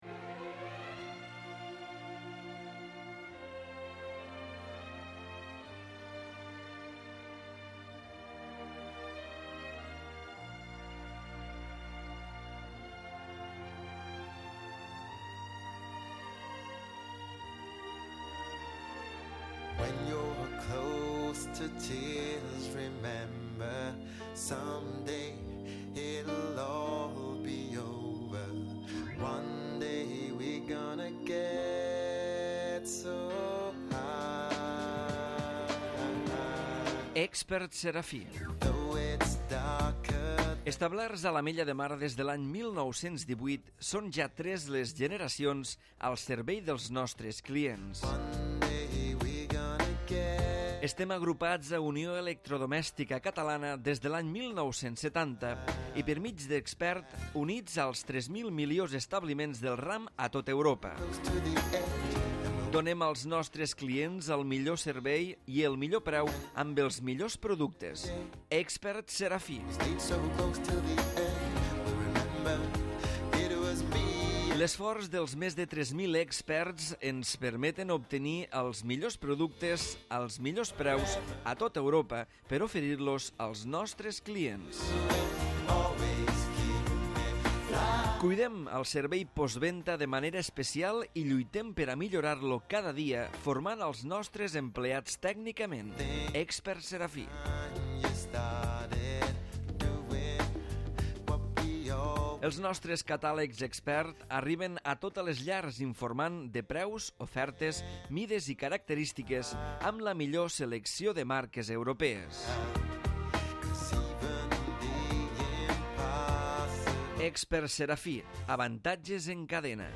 Ple Ordinari de l'Ajuntament de l'Ametlla de Mar celebrat el dijous 23 de juliol de 2015 a les 19'30h.